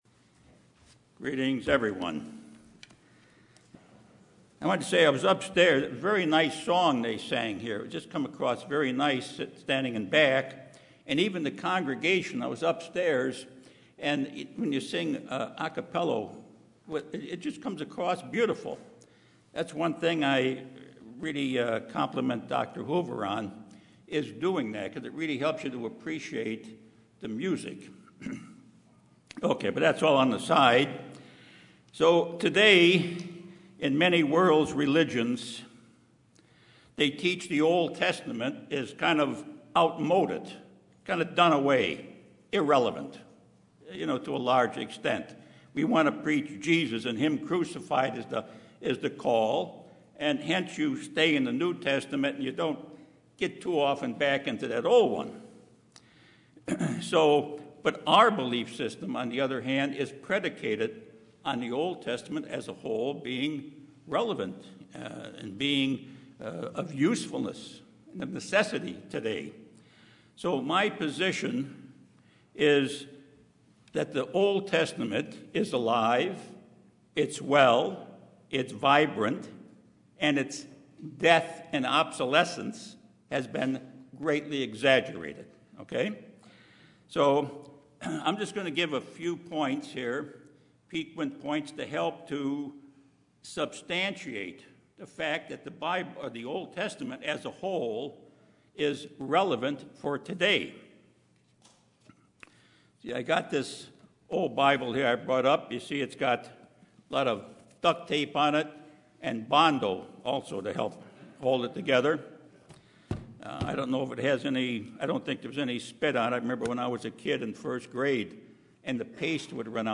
Given in Los Angeles, CA
Print The Old Testament is relevant today, demonstrating the continuity of the Bible from Genesis to Revelation, UCG Sermon Studying the bible?